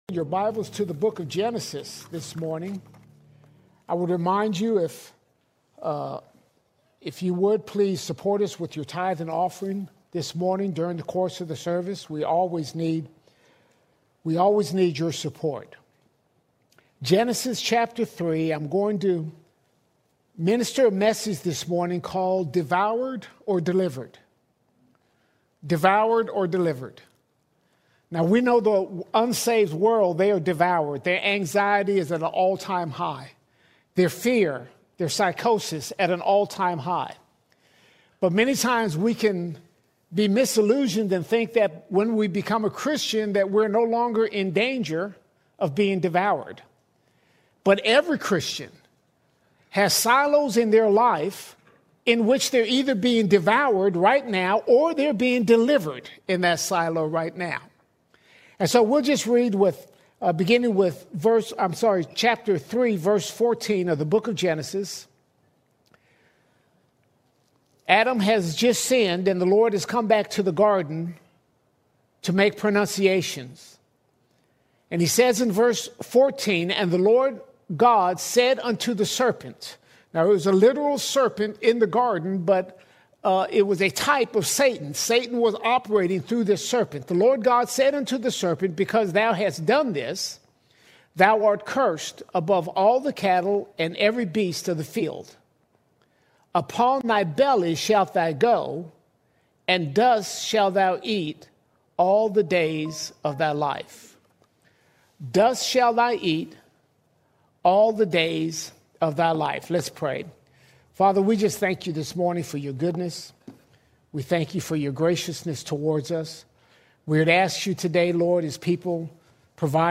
5 January 2026 Series: Sunday Sermons All Sermons Devoured Or Delivered Devoured Or Delivered In our walk with Jesus, there is no neutral ground.